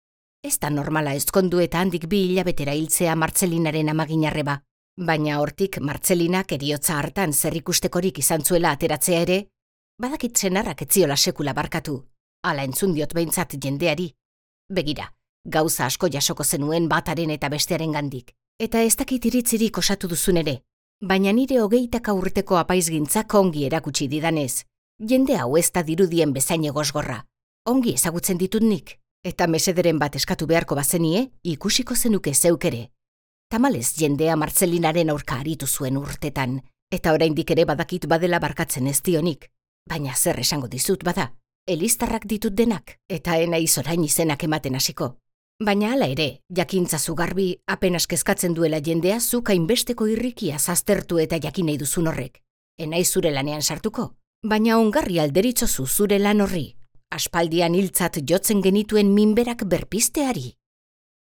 Euskal idazleen audioliburuak paratu ditu sarean eitb-k